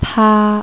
There are 19 initials in Cantonese: (All are pronounced in tone 1 HL)
For eg., when you click "b" here, actually you the sound played is "ba"
p